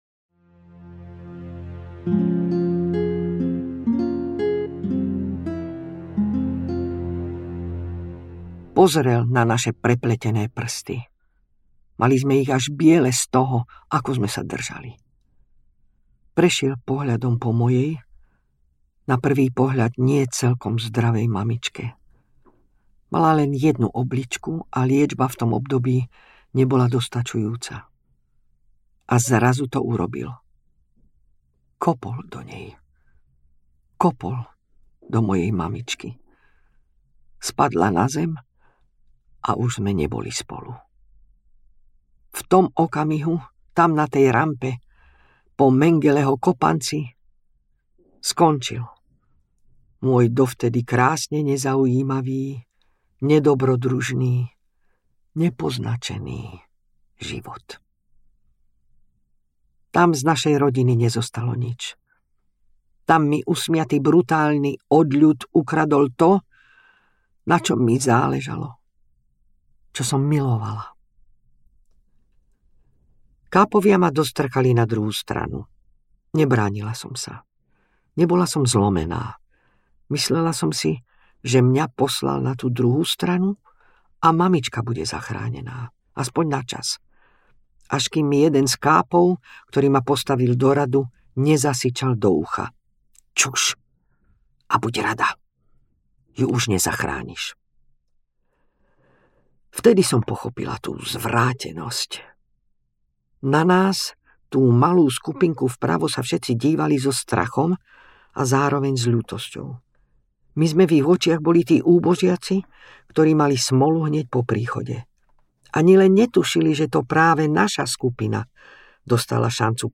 Mengeleho dievča audiokniha
Ukázka z knihy
• InterpretBožidara Turzonovová